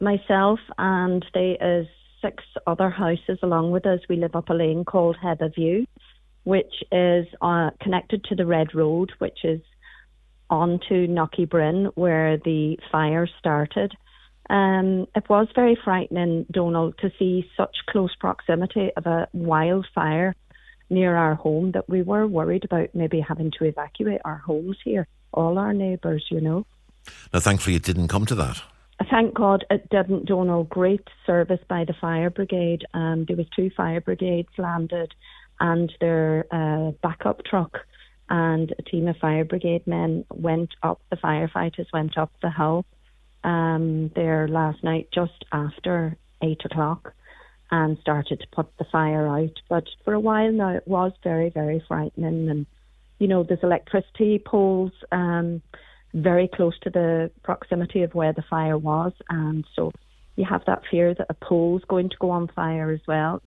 Woman describes fright as Letterkenny gorse fire neared her home